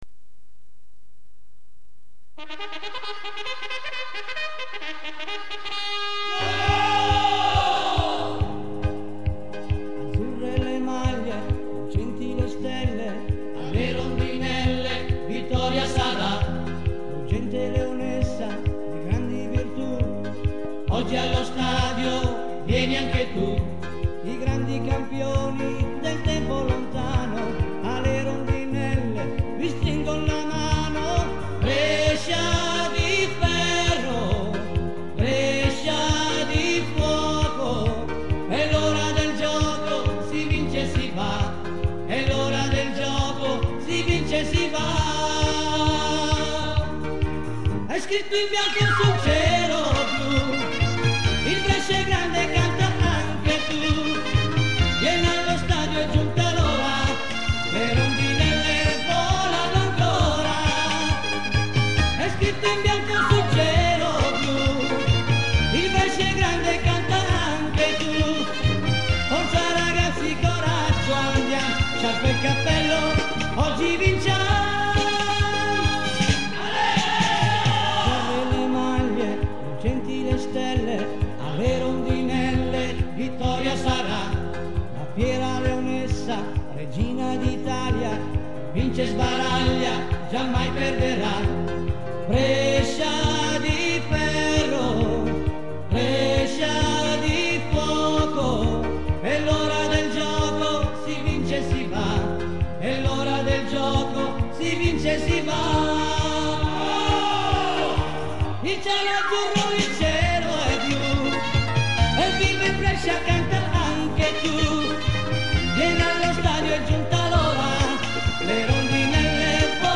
Canzone